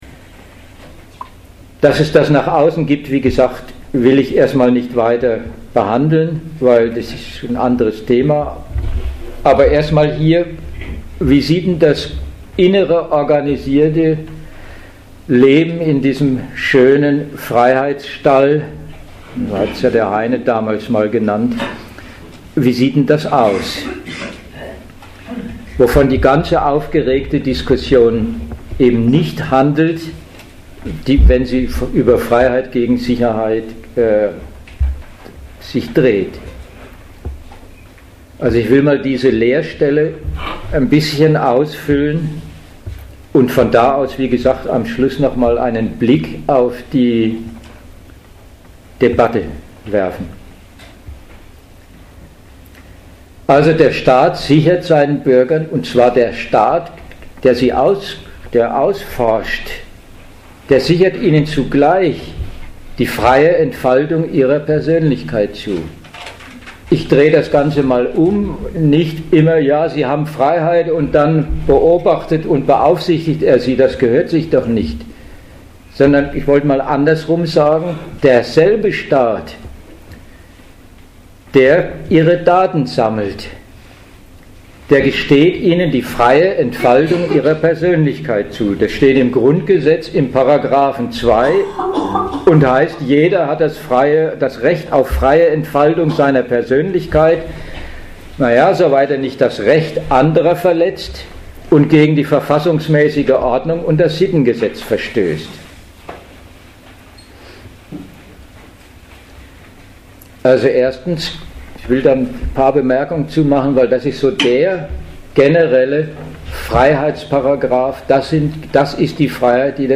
Veranstalter Arbeitskreis Gegenargumente.
Dozent Gastreferenten der Zeitschrift GegenStandpunkt